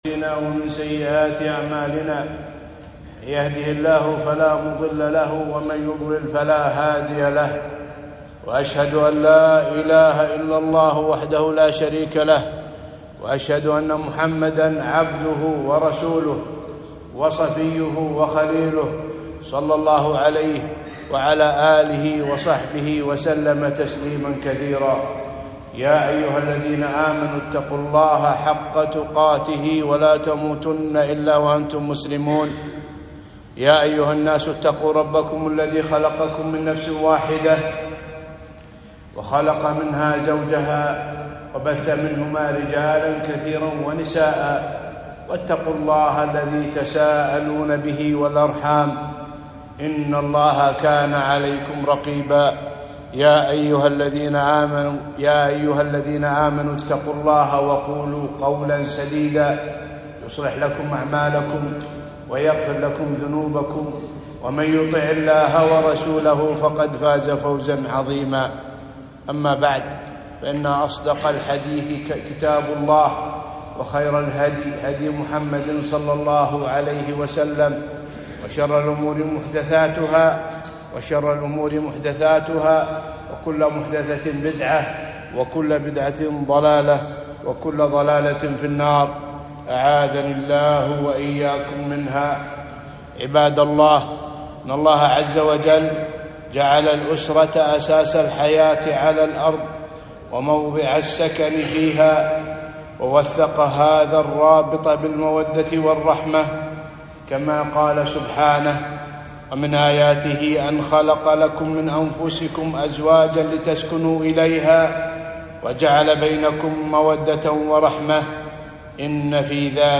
خطبة - الأسرة بناء عظيم